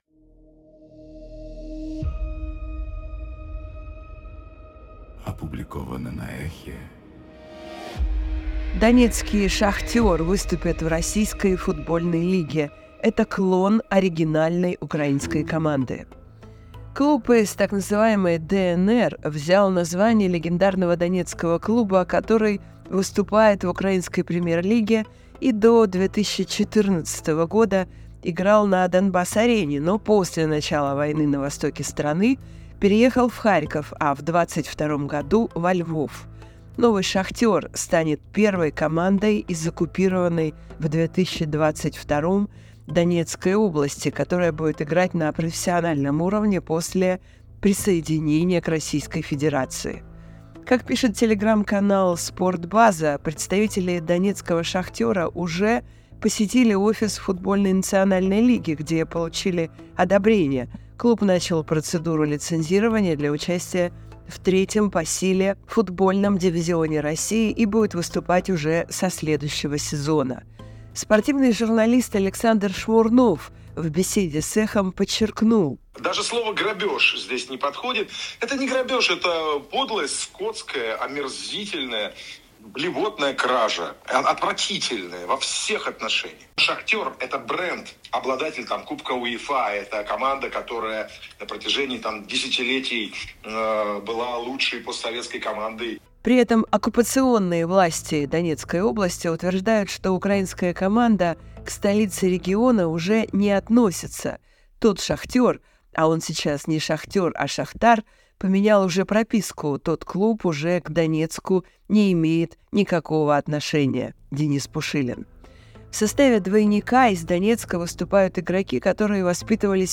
Читает Ольга Бычкова